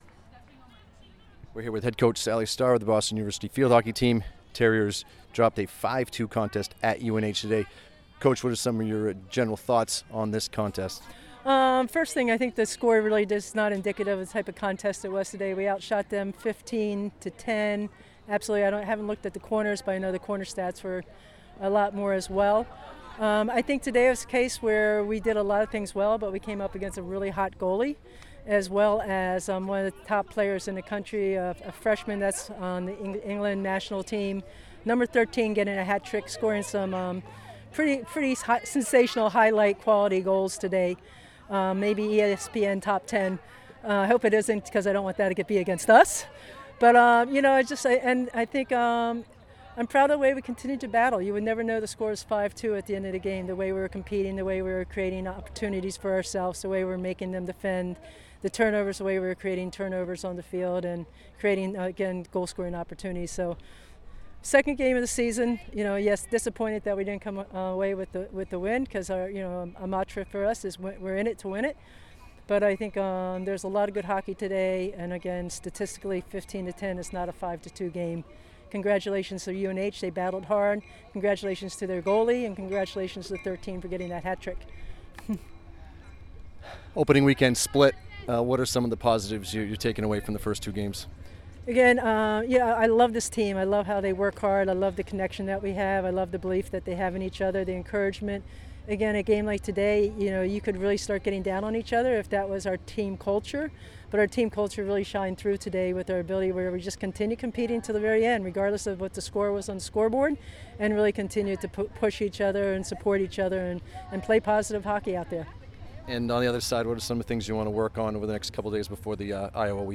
UNH Postgame Interview